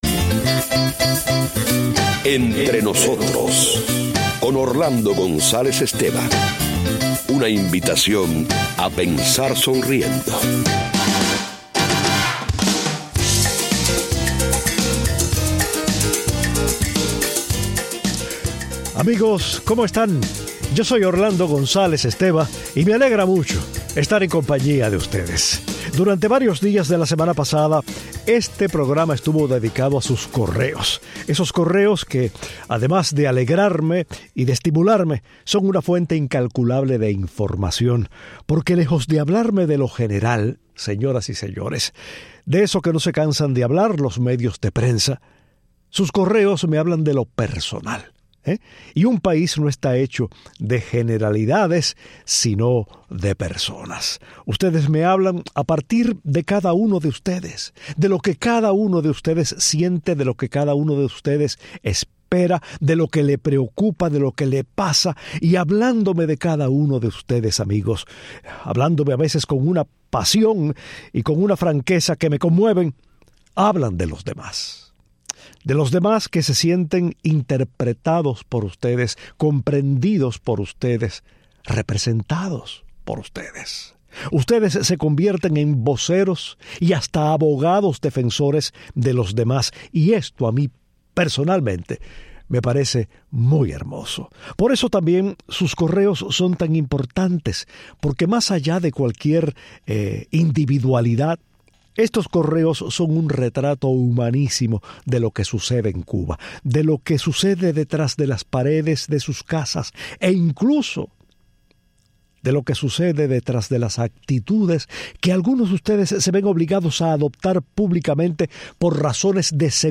Orlando González Esteva lee y comenta correos procedentes de Cuba que denuncian la dramática situación después de las lluvias y la sed de información fidedigna que se padece en ella y que sólo aplacan las transmisiones de Radio Martí.